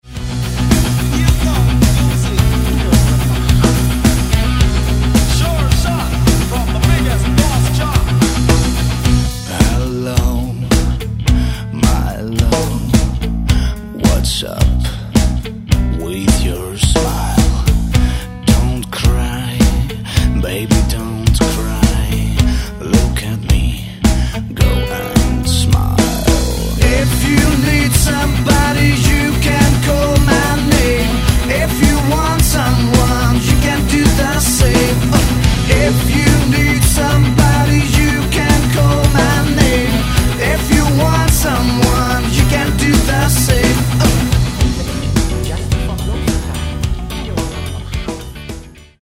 Рок и альтернатива